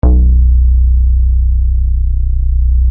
Disco Roller Bass Minimoog B1 roller_bass
roller_bass.mp3